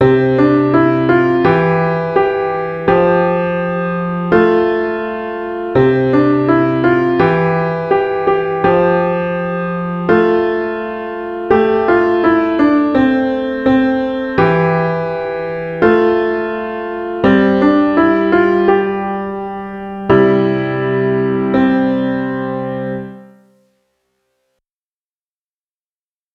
simple, fun piano piece based on the C five tone scale.